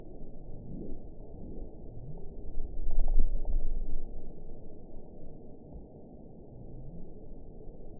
event 921158 date 05/02/24 time 07:14:10 GMT (1 year, 1 month ago) score 8.49 location TSS-AB05 detected by nrw target species NRW annotations +NRW Spectrogram: Frequency (kHz) vs. Time (s) audio not available .wav